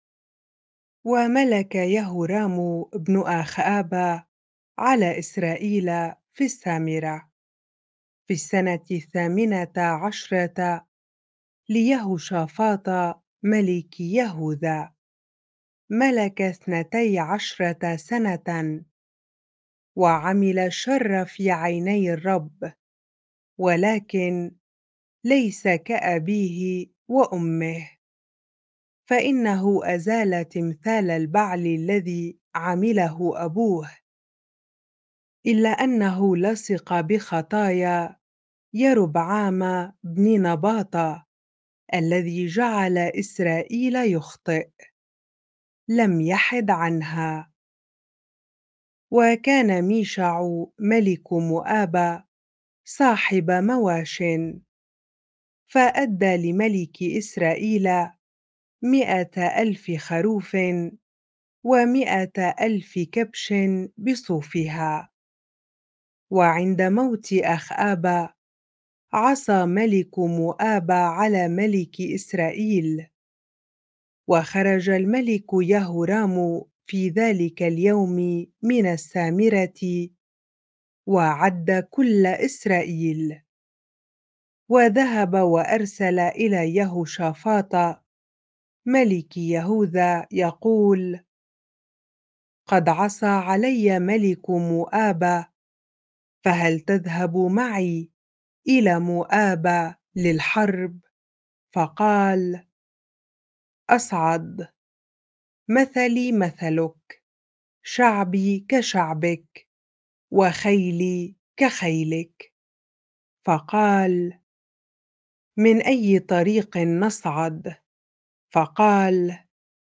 bible-reading-2 Kings 3 ar